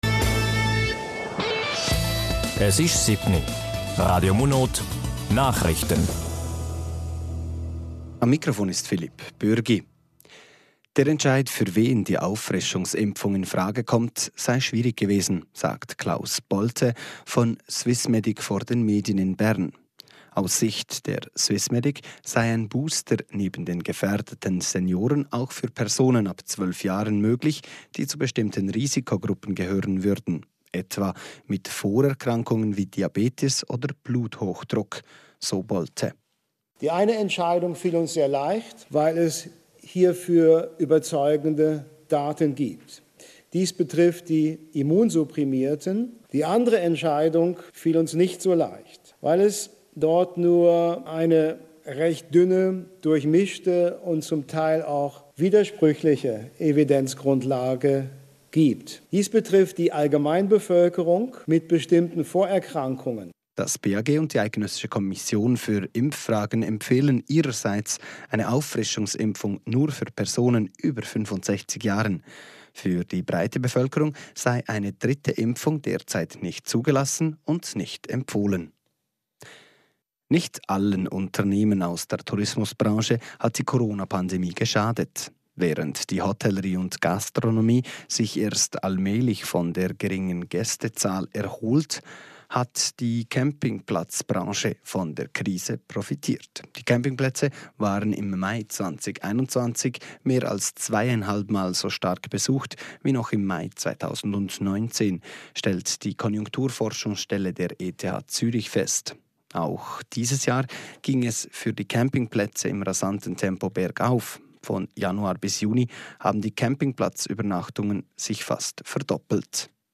Mannschaft in der Saison 2022/23 Radio-Interview anlässlich des 25-jährigen Vereinsjubiläums (ab 5:00)
Live-Radio-Interview.mp3